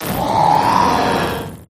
appletun_ambient.ogg